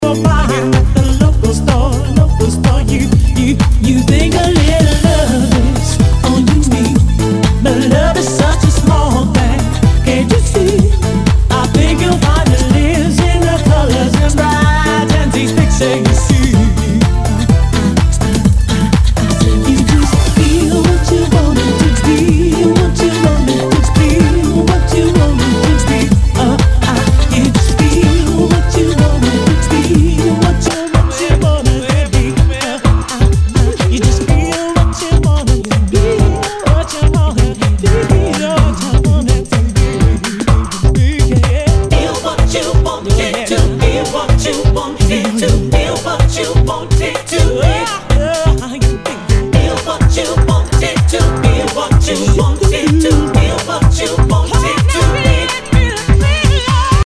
HOUSE/TECHNO/ELECTRO
ナイス！ヴォーカル・ハウス・ミックス！
全体にチリノイズが入ります